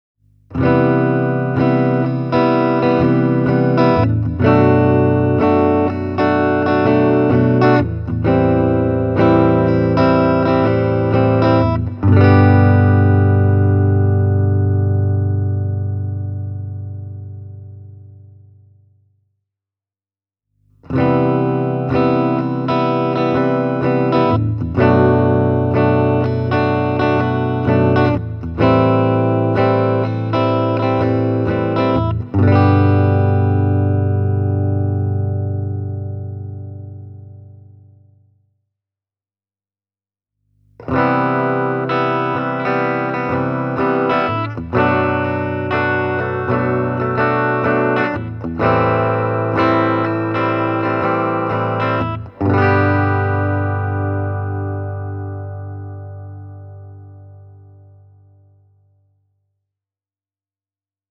Seymour Duncanin Antiquity-mikrofonit antavat todellakin puiden soida, ja näiden humbuckereiden tarkkavainen, dynaaminen ja avoin ääni on positiivisella tavalla kuiva: